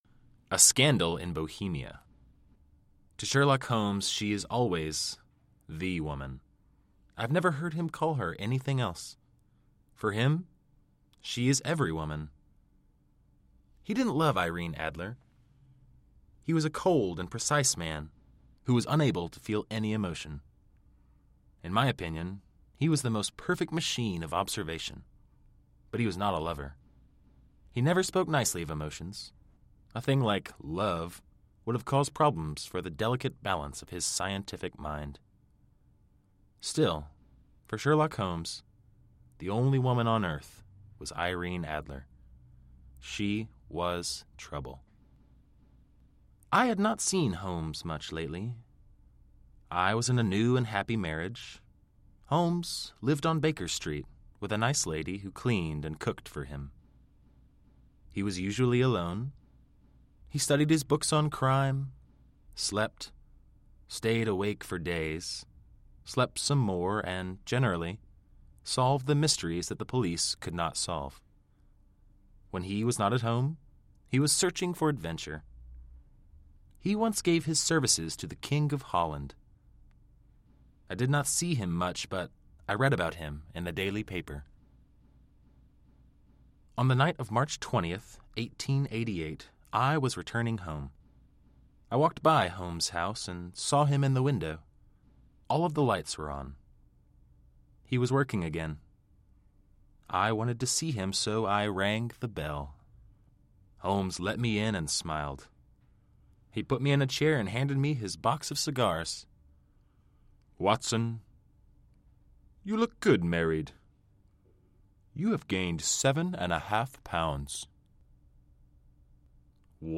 Audio kniha
Ukázka z knihy
Povídka od světoznámého spisovatele Arthura Conana Doyle je převedena do zjednodušené verze a nahrána rodilým mluvčím.